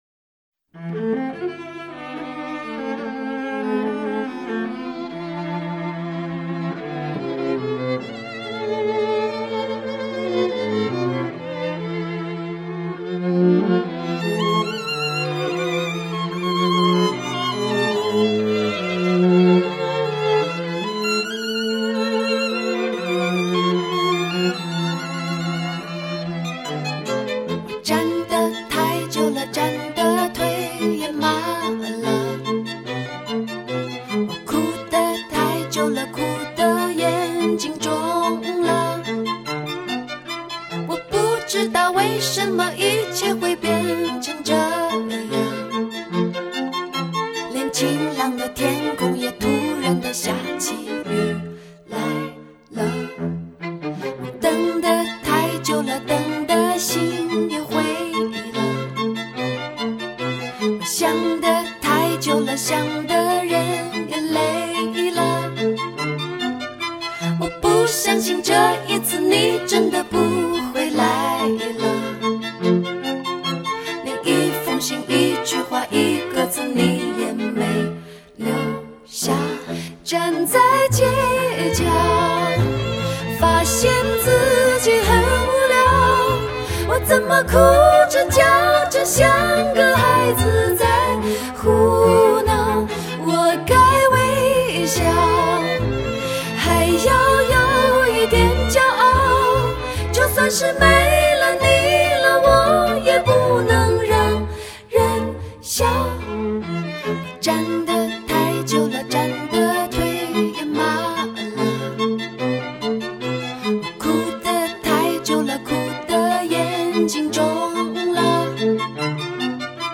作为中国学院派代表的流行女歌手之一